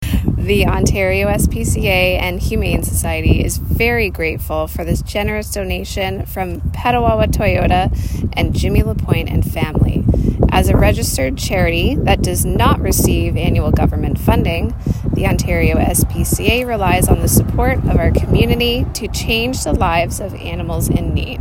expressed the significance of such donations in an interview with myFM.